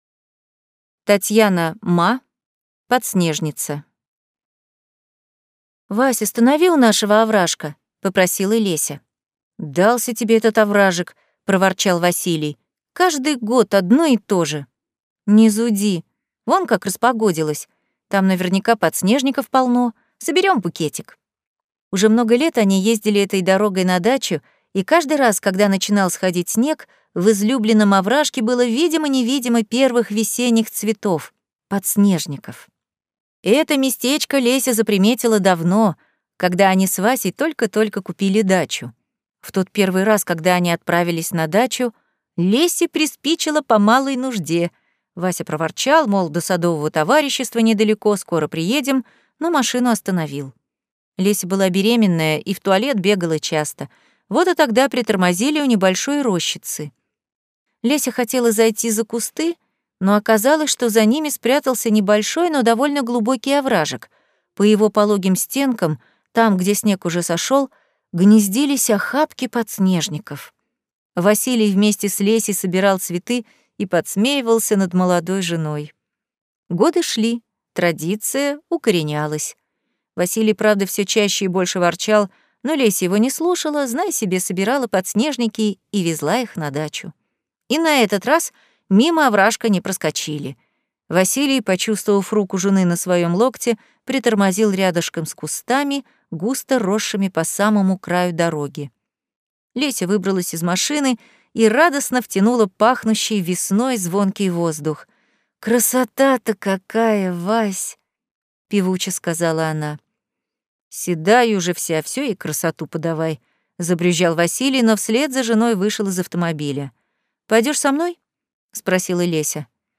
Аудиокнига Подснежница | Библиотека аудиокниг